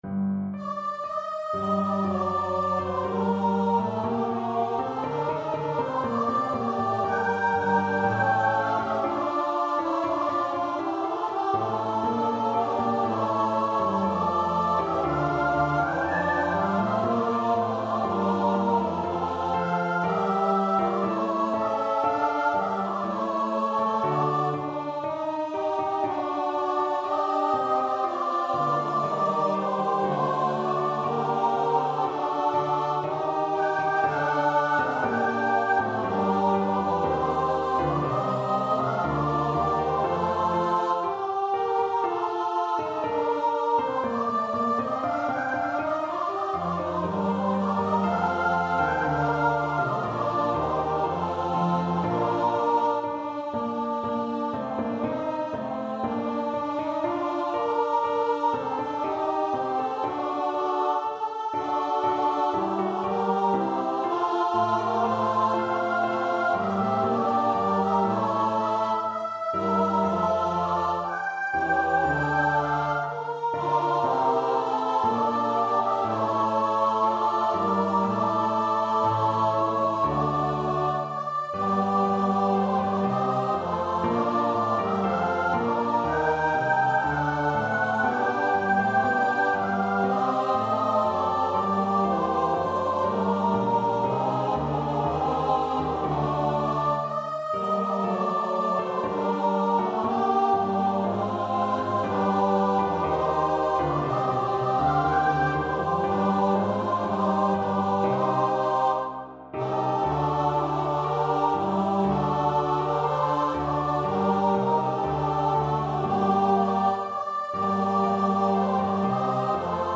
Sing unto God (Judas Maccabaeus) Choir version
Free Sheet music for Choir
Choir  (View more Intermediate Choir Music)
Classical (View more Classical Choir Music)